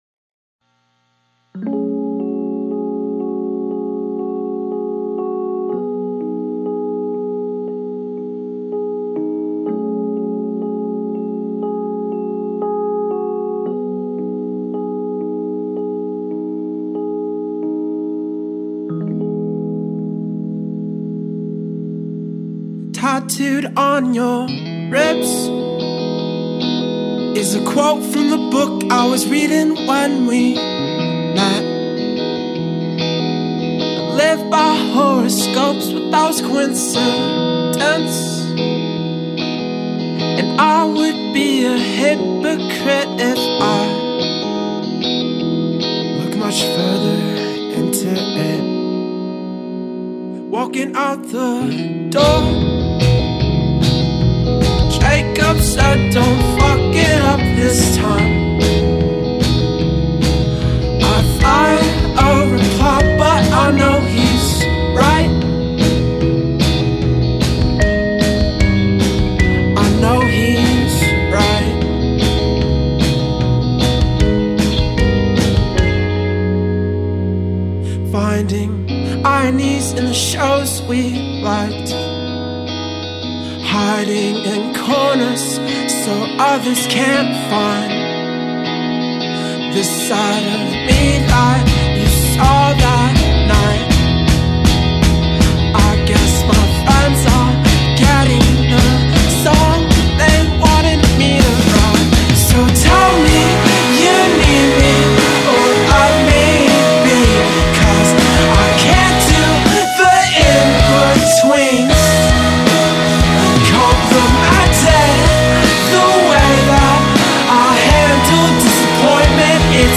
it’s the dynamic, jangly rock tune